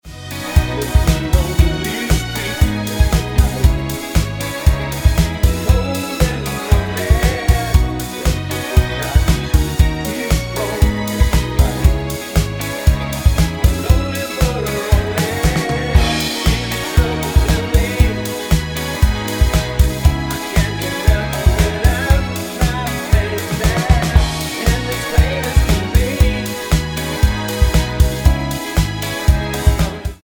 Tonart:F# mit Chor
Die besten Playbacks Instrumentals und Karaoke Versionen .